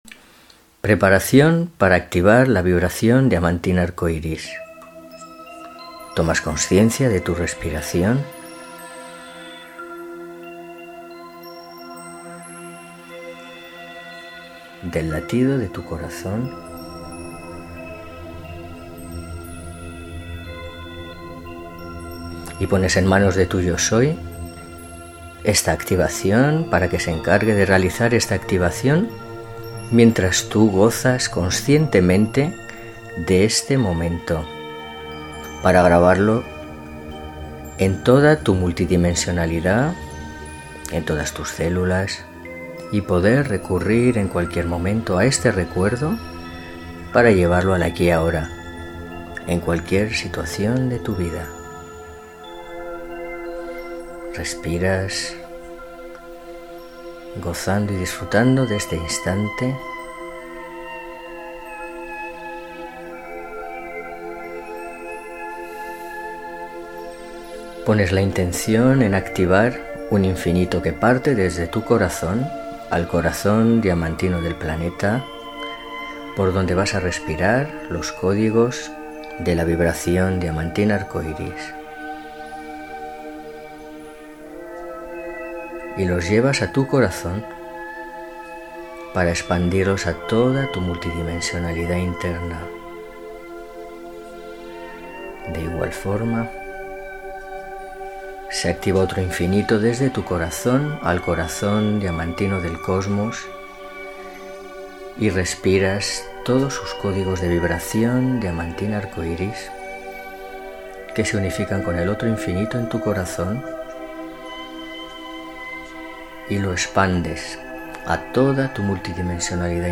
Solo tienes que respirar la música mientras disfrutas del proceso, de esta forma quedarán grabadas en ti estas nuevas memorias y recuerdos para que puedas recurrir a ellos en cualquier momento de tu vida y elevar asi tu vibración, acceder a nuevas y mágicas realidades en el aquí y ahora.